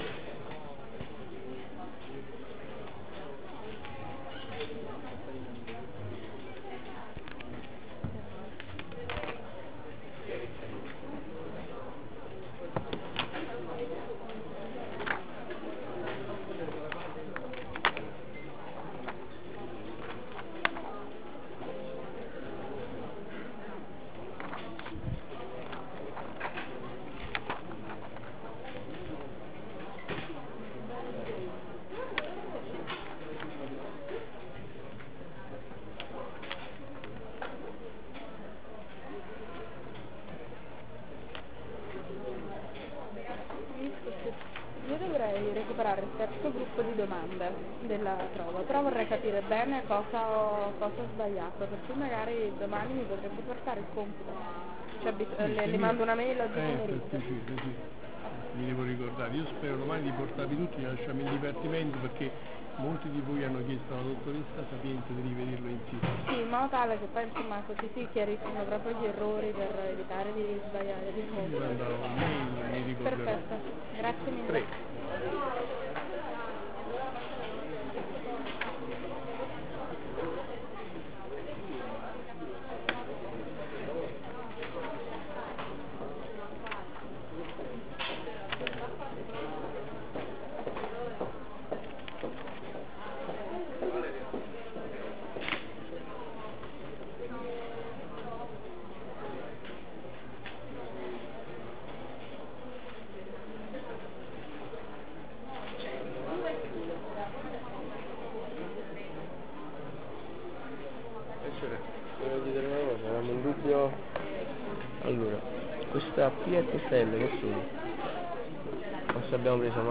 lezione 27-11.WAV